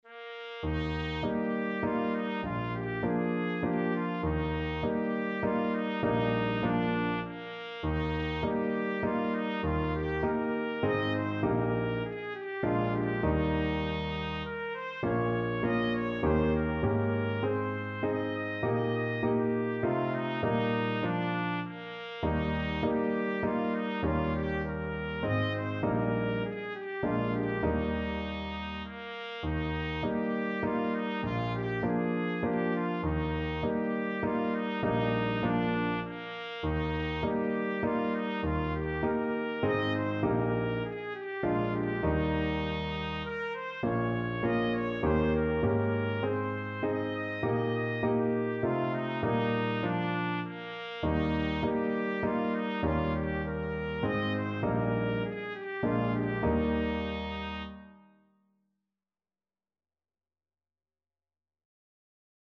3/4 (View more 3/4 Music)
Flowing
Traditional (View more Traditional Trumpet Music)
Scottish